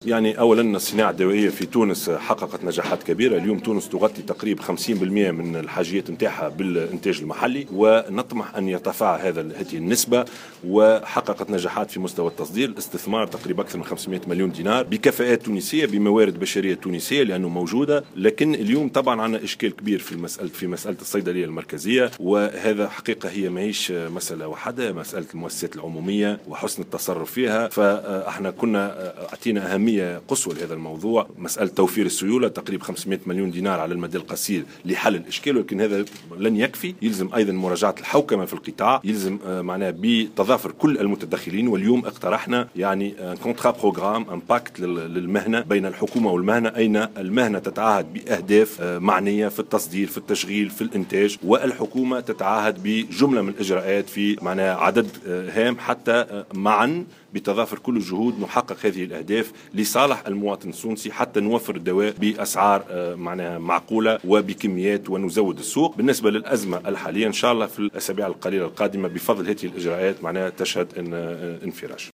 على هامش ندوة حول الادوية في الحمامات